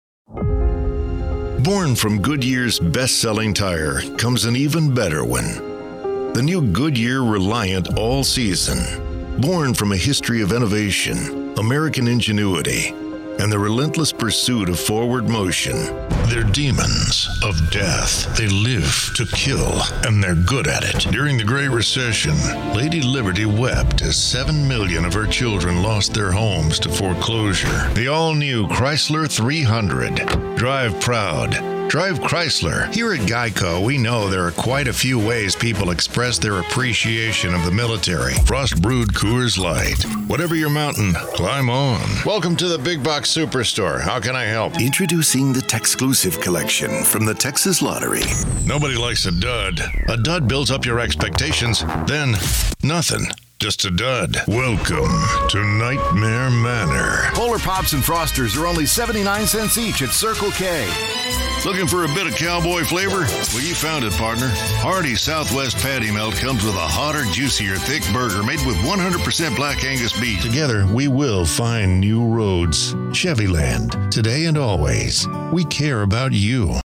Smooth, warm, conversational, authentic and inviting, yet capably diverse
Authentic Texan
Middle Aged
I have a great home studio with Neumann mic, Pro Tools, isolated vocal booth and Source Connect!!